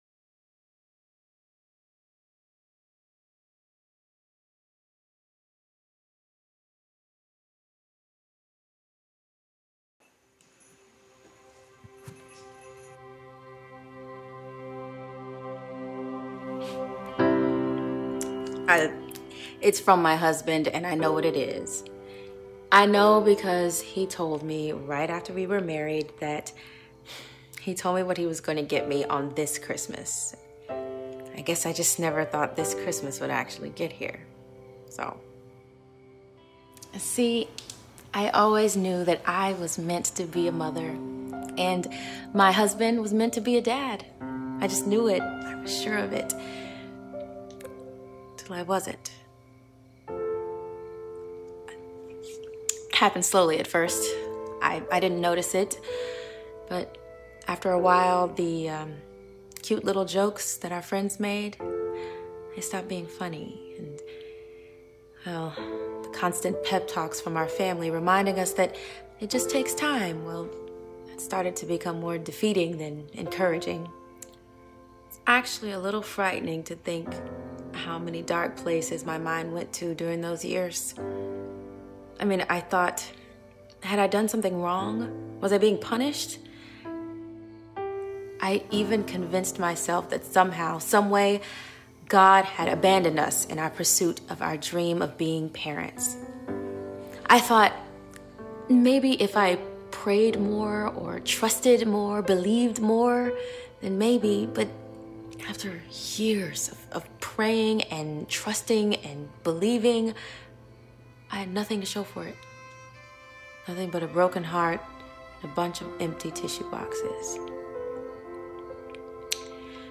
Sermons | CrossWinds Church